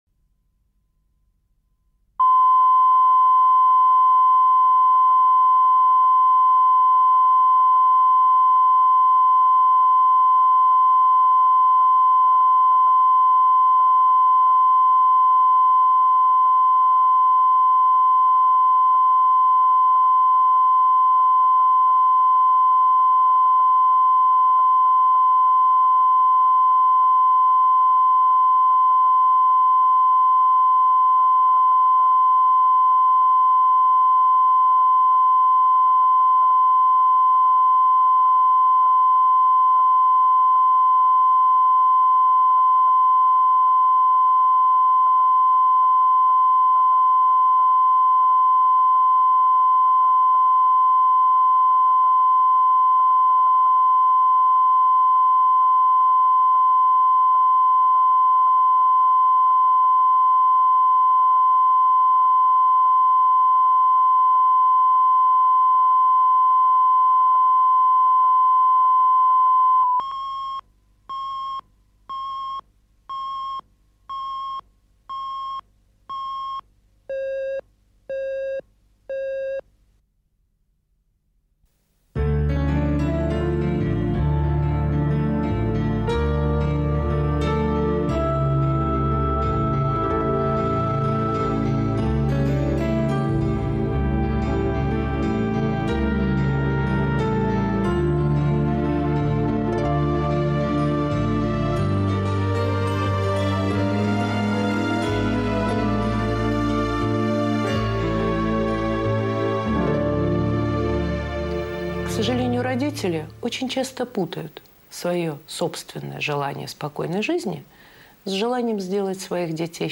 Аудиокнига Стоимость любви | Библиотека аудиокниг
Прослушать и бесплатно скачать фрагмент аудиокниги